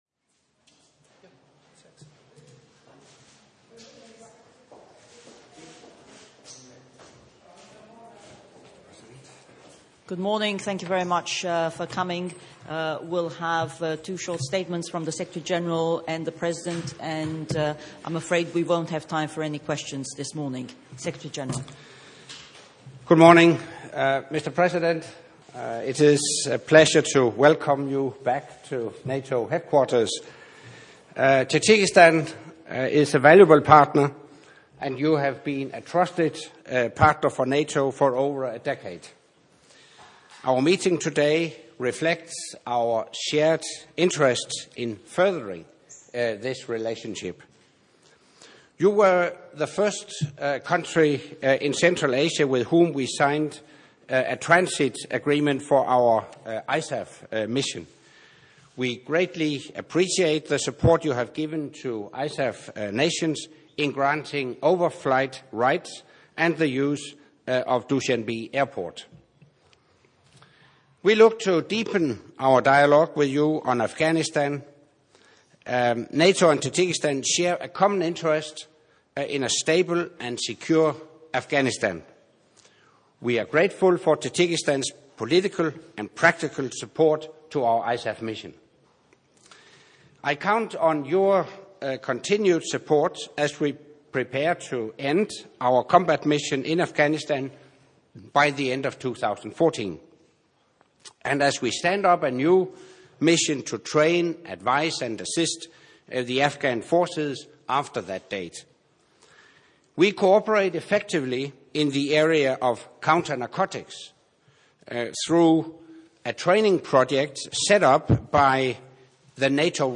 Joint press point with NATO Secretary General Anders Fogh Rasmussen and President Emomali Rahmon of the Republic of Tajikistan